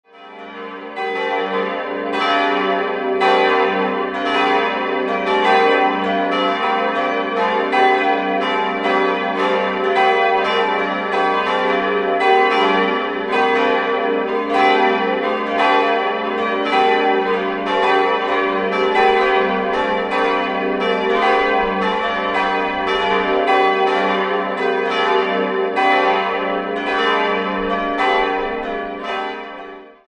Die Glocken g' (948 kg) und d'' (384 kg) stammen aus der Gießerei Friedrich Wilhelm Schilling und wurden im Jahr 1965 gegossen. Die beiden mittleren dürften um das Jahr 1500 in Nürnberg gegossen worden sein.